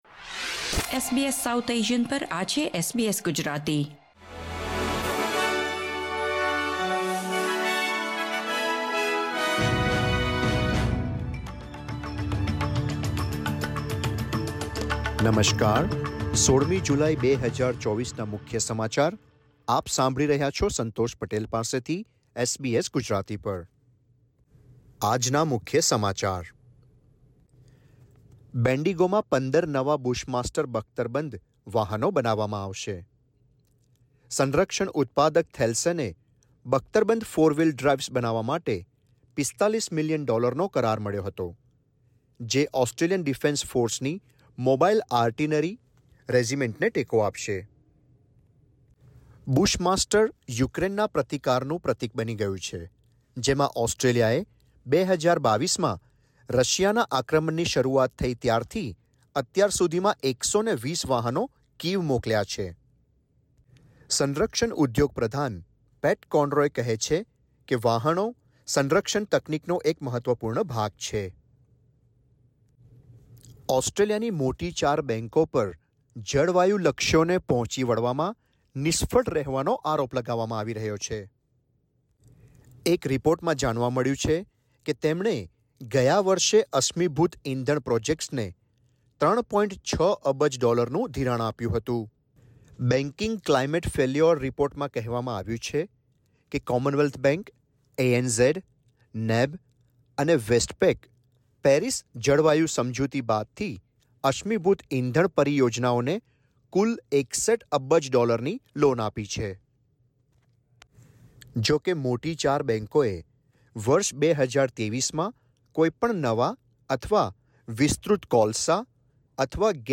SBS Gujarati News Bulletin 16 July 2024